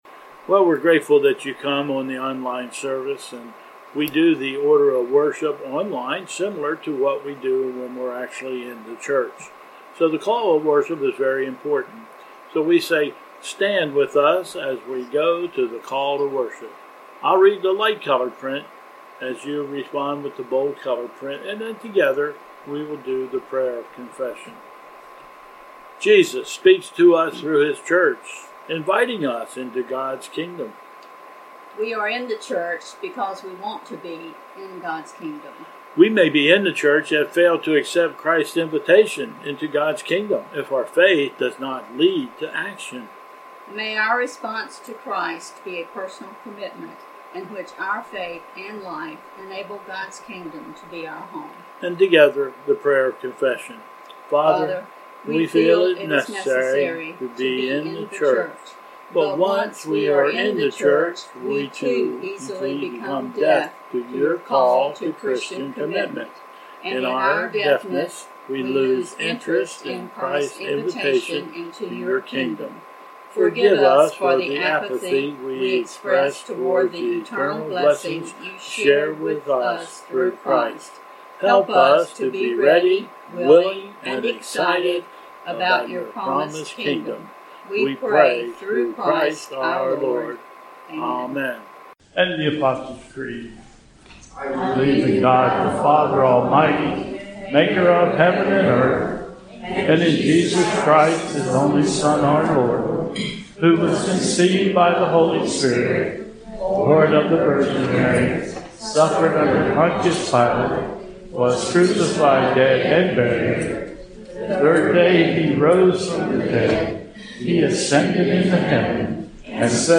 Bethel 10/11/20 Service
Processional